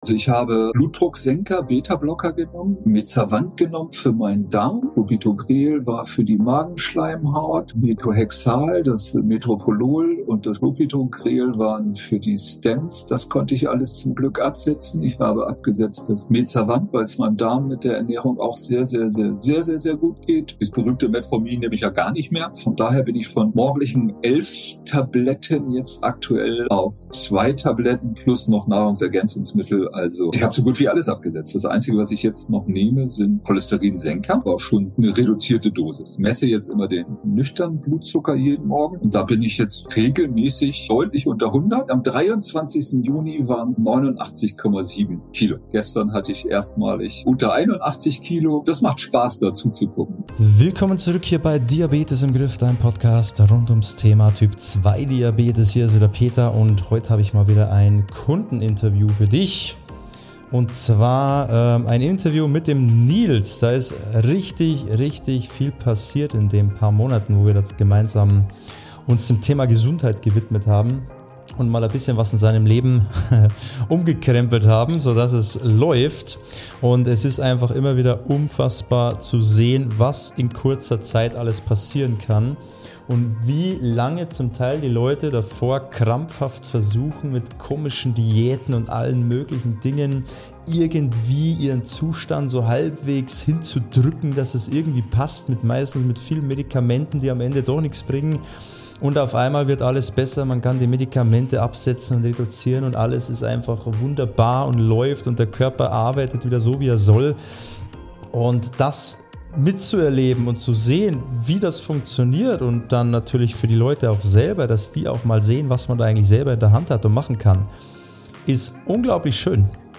Ein sehr inspirierender Erfahrungsbericht.